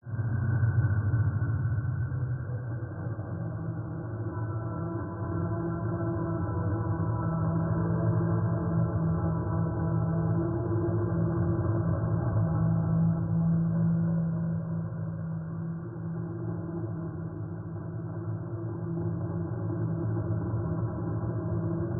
Эта страница предлагает коллекцию звуков, воссоздающих атмосферу психиатрической больницы: отдаленные голоса, шаги по пустым коридорам, металлический лязг решеток.
Звуки психиатрической больницы: Тишина пустого дурдома в ночной темноте